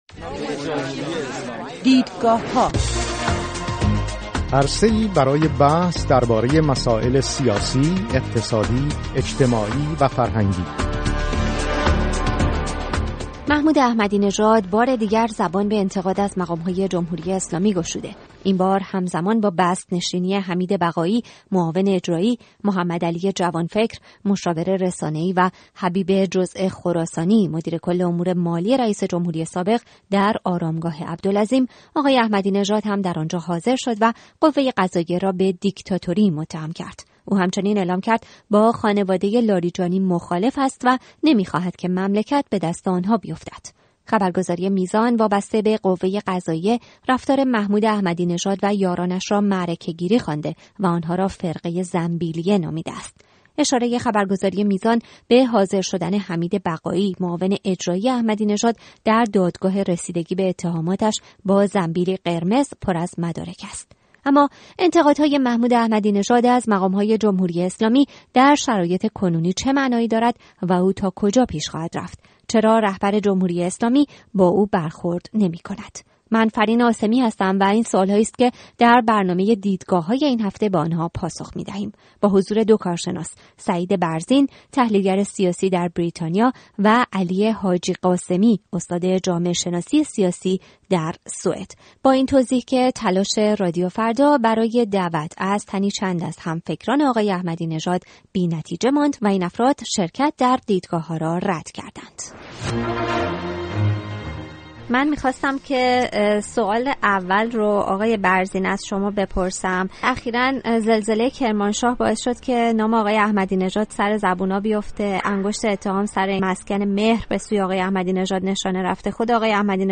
با حضور دو کارشناس